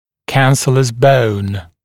[ˈkænsələs bəun][ˈкэнсэлэс боун]губчатое вещество кости, губчатая кость